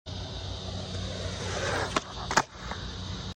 Just a kickflip.